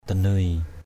/d̪a-nøɪ/ (t.) vang, vang dậy = retentir, résonner. resonate, resound. danây sap saai éw dnY xP x=I e| vang tiếng anh gọi. sap danây xP dnY tiếng vang. préw danây...